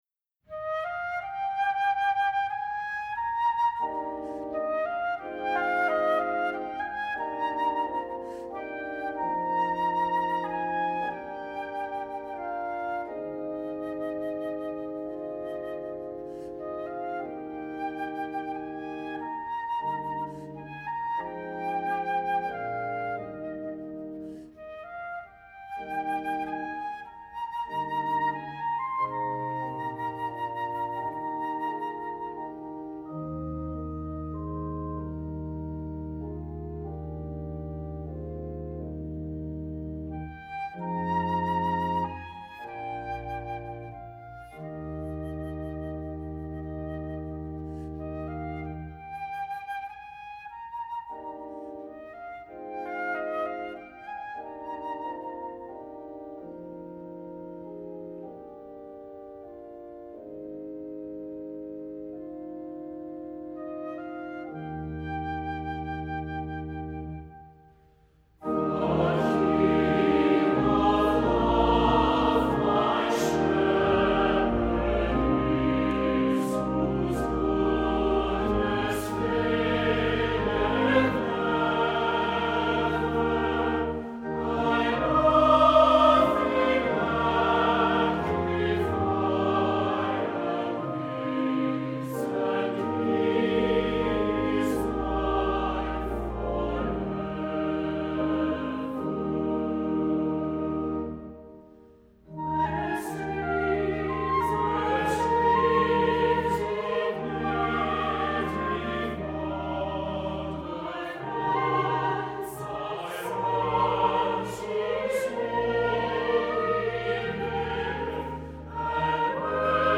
Voicing: Congregation, SAB, Flute, and Organ